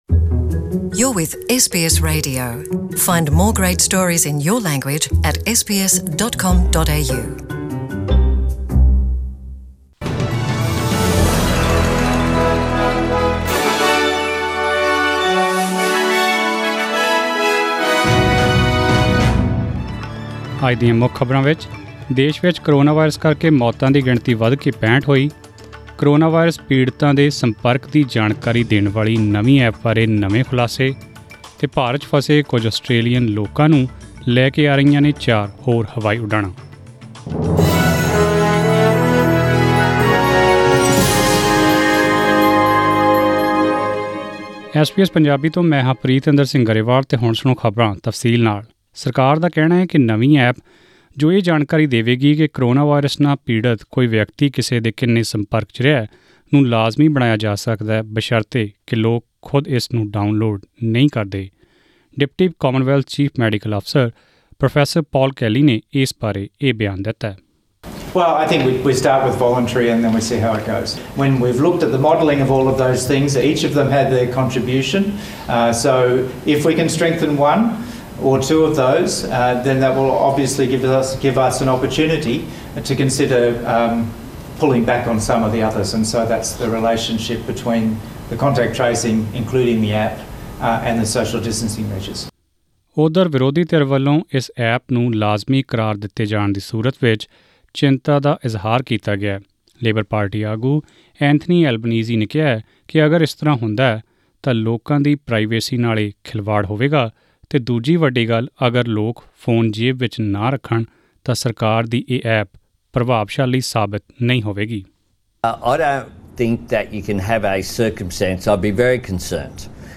Australian News in Punjabi: 17 April 2020